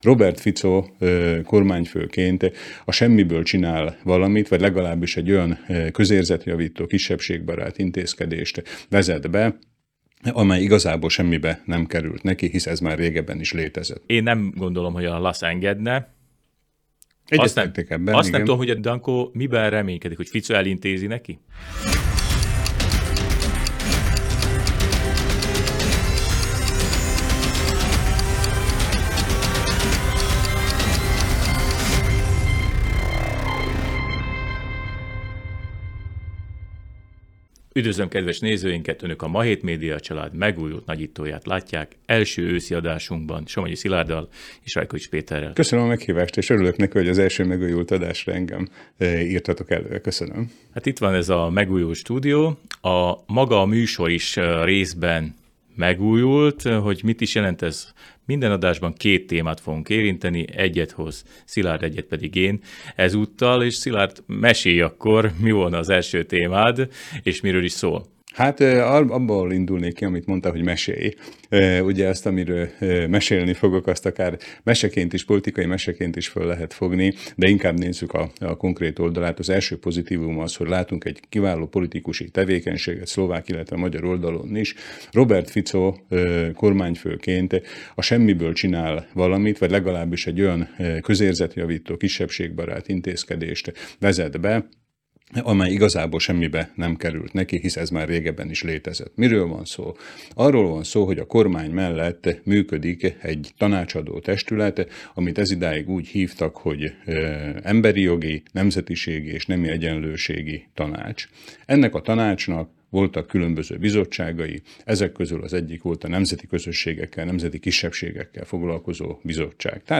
Népszerű elemző műsorunk, a Nagyító új stúdióban és formában jelentkezik, de a témák hátterére továbbra is ránagyítunk.